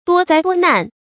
多灾多难 duō zāi duō nàn
多灾多难发音